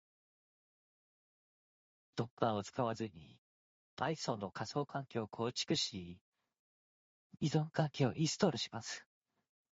別の男性モデルも試してみましょう。
声が若々しくなりましたね！